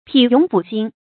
擗踊拊心 pǐ yǒng fǔ xīn
擗踊拊心发音